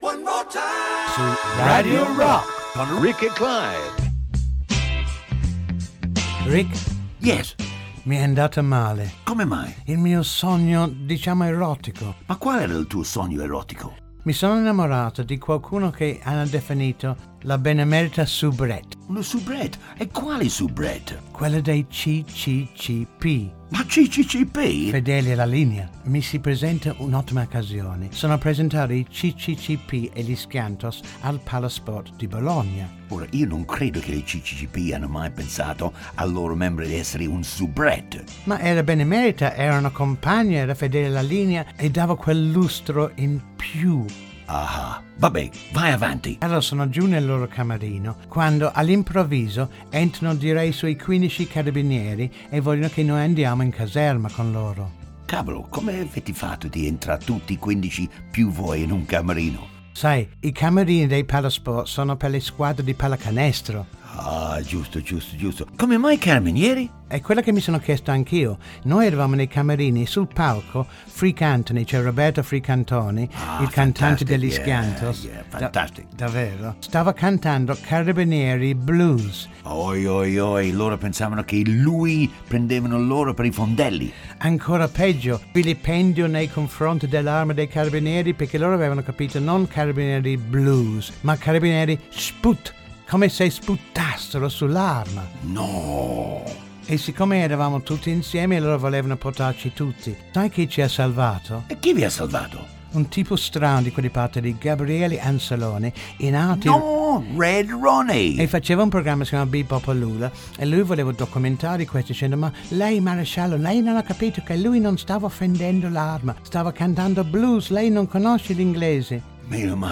Voci: Rick Hutton, Clive Griffiths.